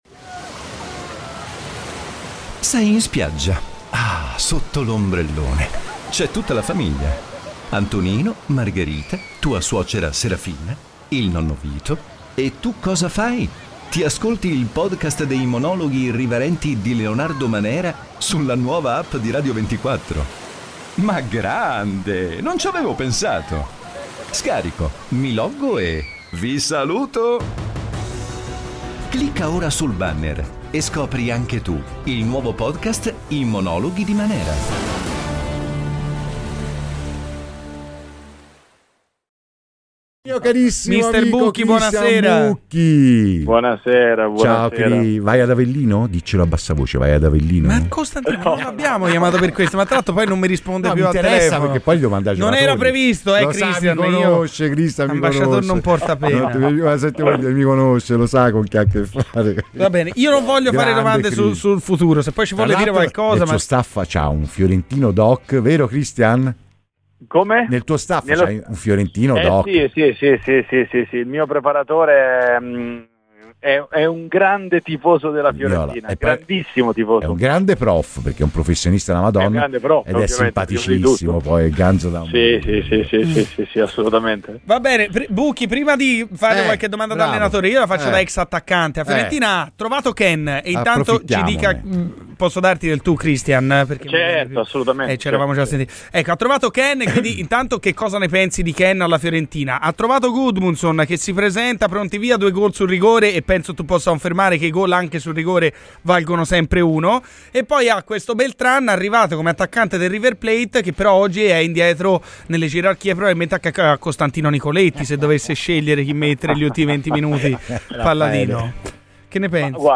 ex attaccante ora allenatore, è intervenuto a Radio FirenzeViola durante la trasmissione "Garrisca al vento" e ha parlato del momento che sta vivendo la squadra viola: